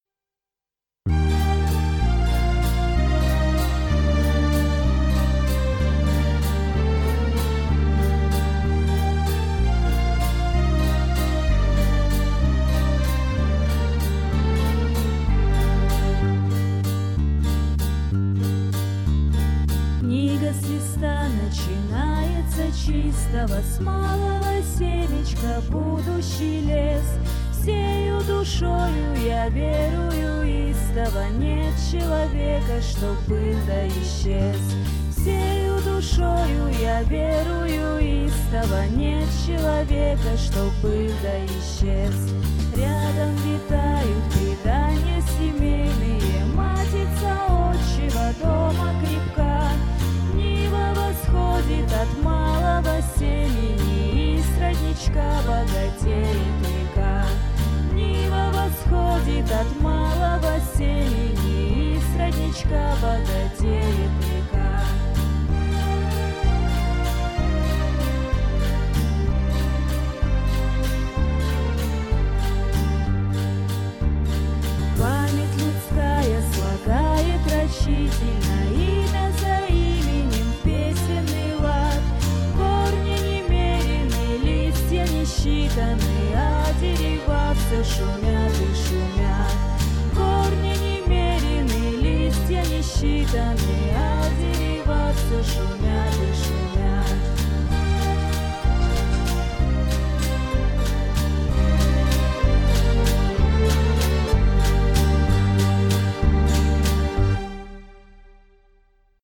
Задушевная мелодия сразу была подхвачена земляками, и кто-то даже предлжил сделать её гимном наших традиционных встреч!